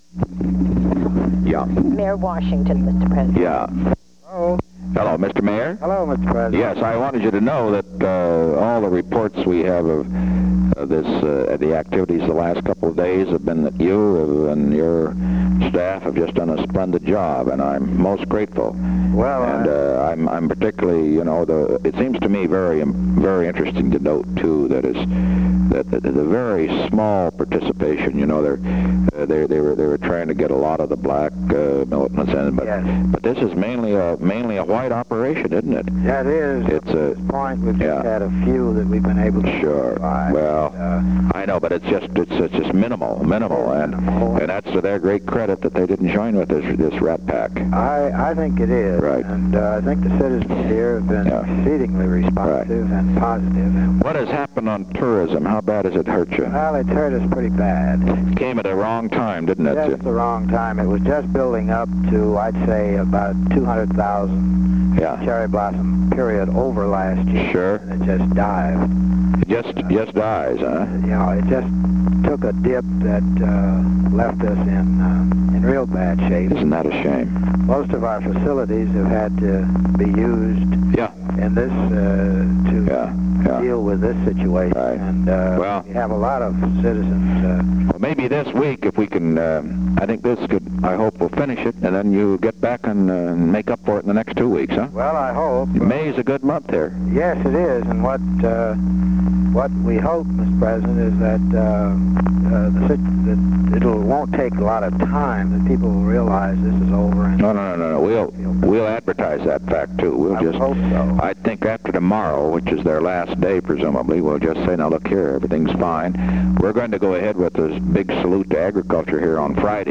Conversation No. 2-74
Location: White House Telephone
The President talked with Walter E. Washington.